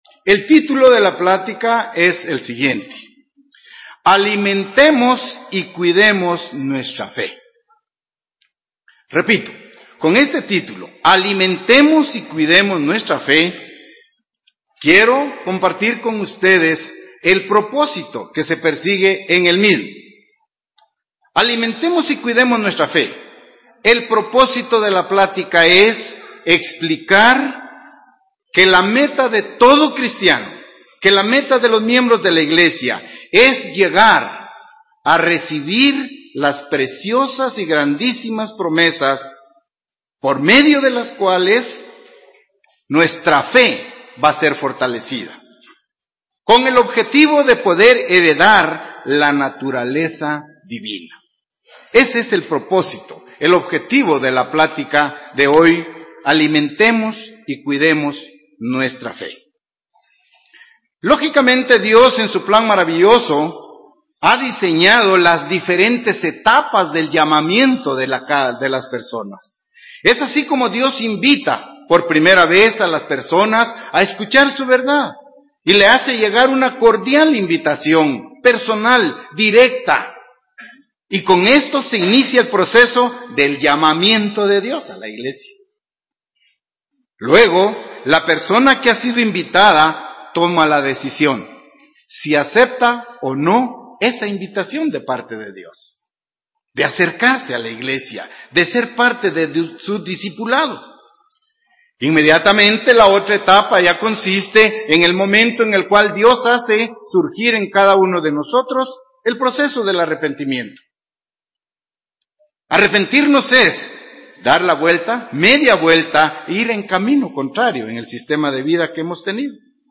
Sermones
Given in Ciudad de Guatemala